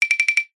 Кошка барабанит лапками - Мультфильмовый стиль